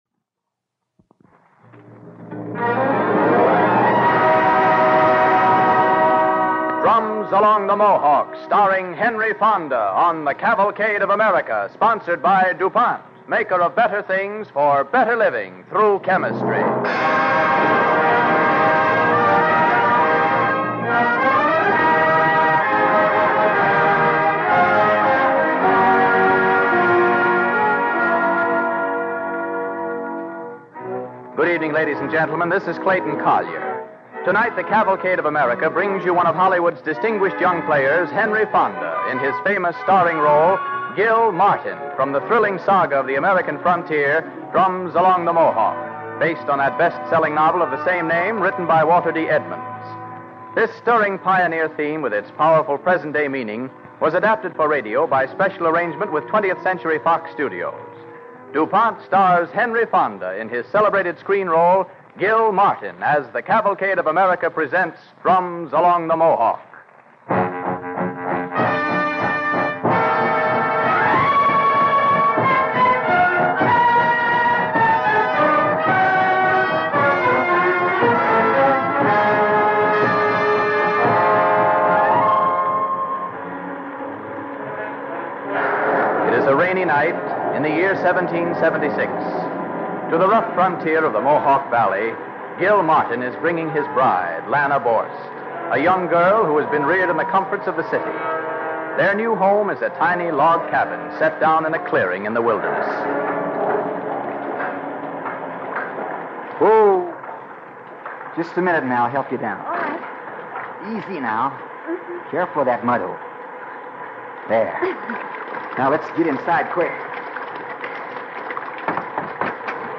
starring Henry Fonda